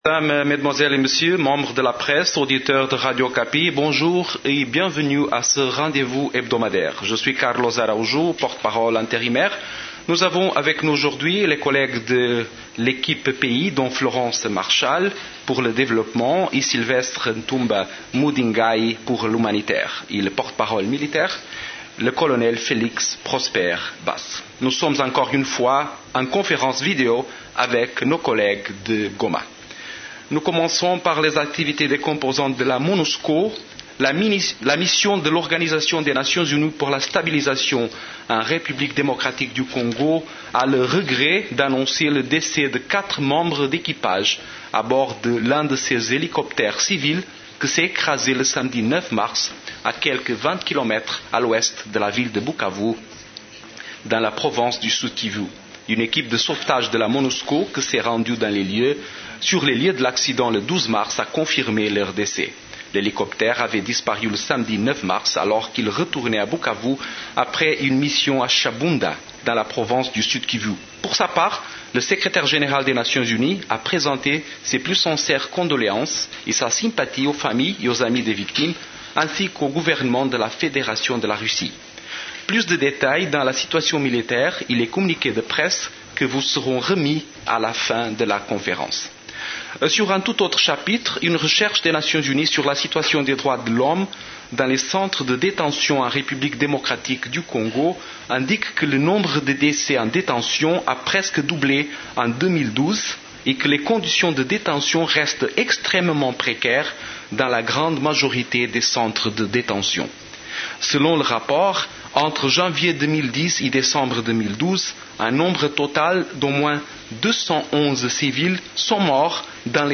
Conférence du 13 mars 2013
La conférence hebdomadaire des Nations unies du mercredi 13 mars a porté sur les sujets suivants:
Voici le verbatim de cette conférence de presse hebdomadaire.